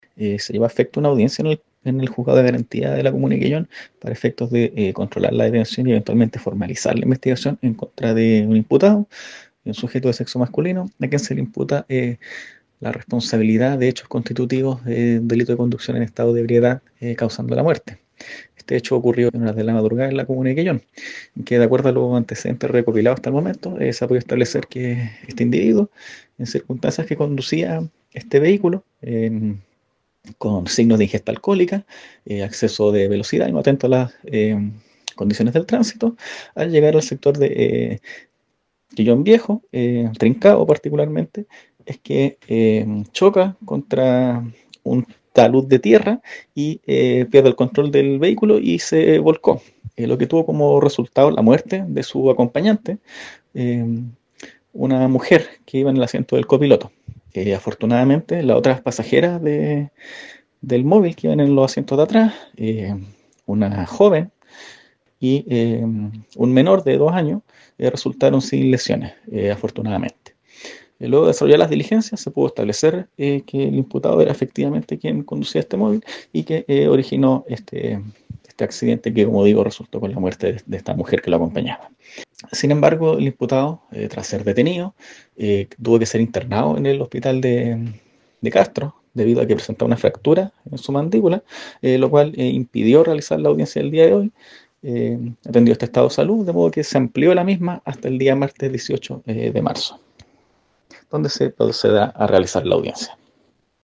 El fiscal Fabián Fernández expuso los antecedentes del caso, aclarando que era el hombre quien iba manejando el vehículo y la mujer que perdió la vida, lo hacía de copiloto.